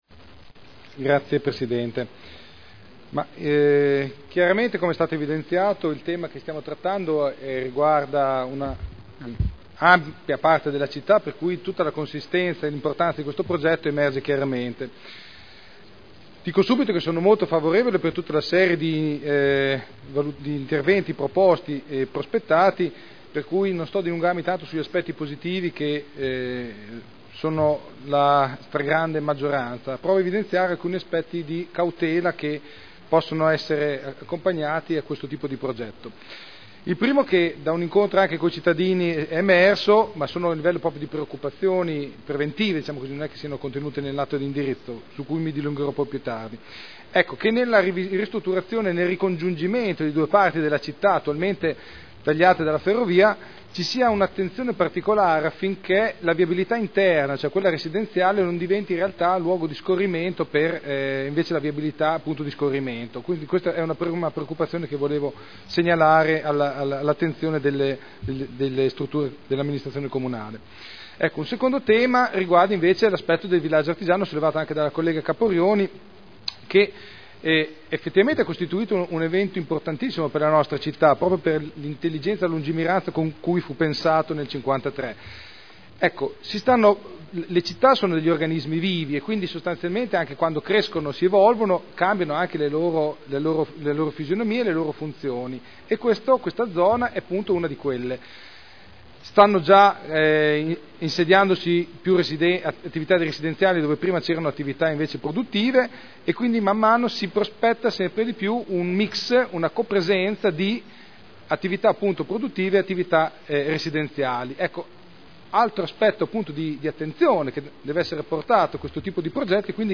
Enrico Artioli — Sito Audio Consiglio Comunale
Seduta del 01/02/2010. La Città della riqualificazione – Programma di riqualificazione urbana per il quadrante urbano di Modena Ovest – Approvazione del documento di indirizzo